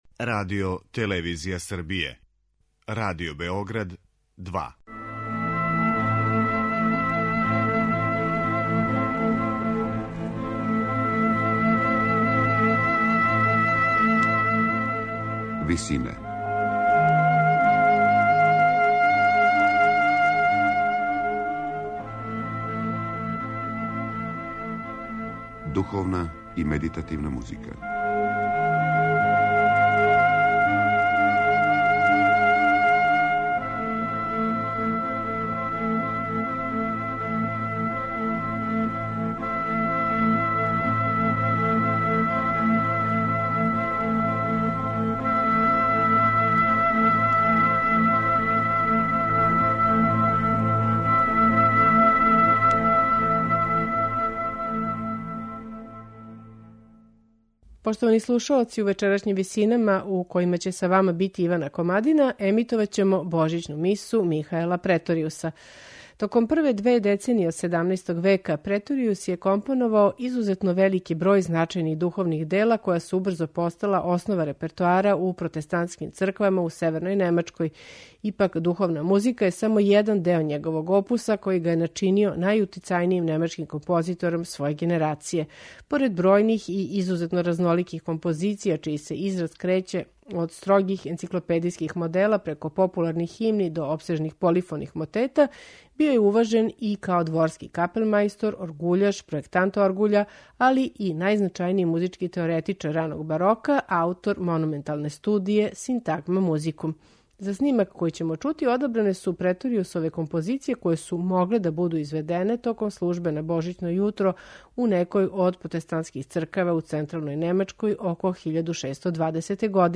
Извођачи су: ансамбл Gabrieli Consort & Players , хор дечака и конгрегацијски хор катедрале у Роскилу, под управом Пола Мек Криша.